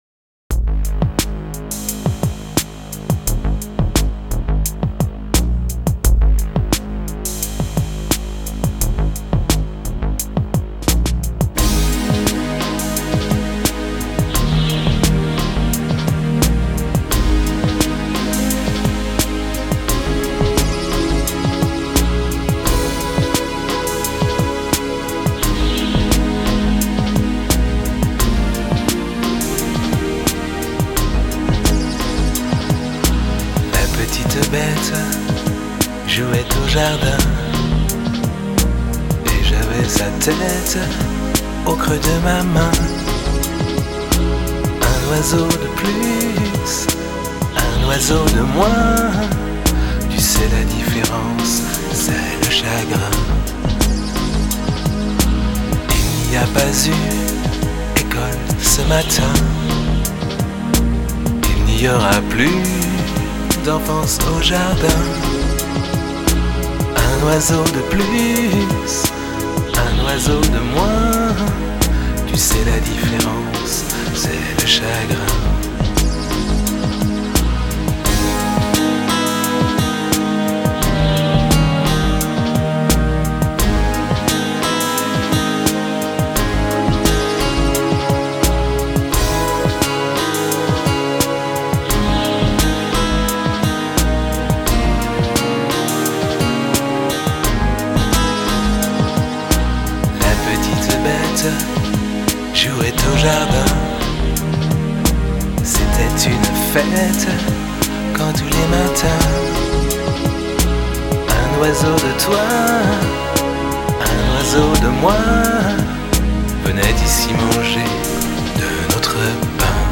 Разок еще свой любимый инструментал прицеплю...